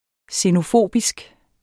Udtale [ senoˈfoˀbisg ]